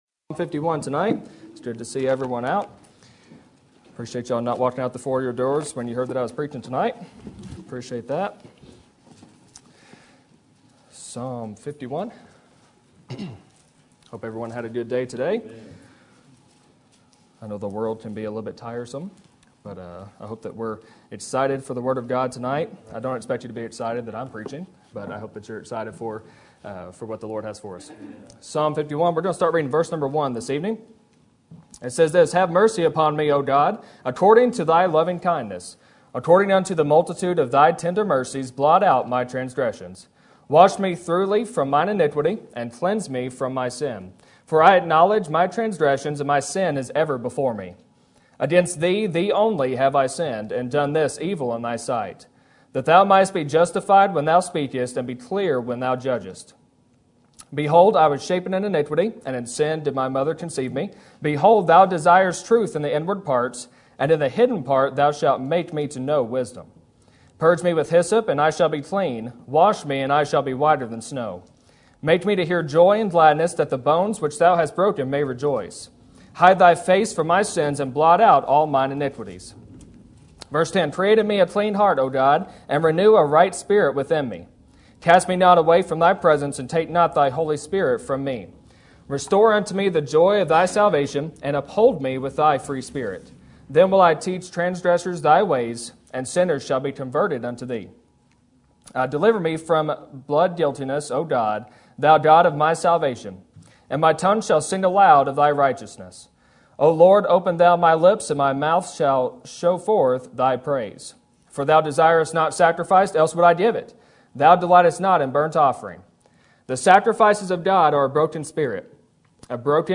Sermon Topic: General Sermon Type: Service Sermon Audio: Sermon download: Download (24.66 MB) Sermon Tags: Psalm Sorry David Relationship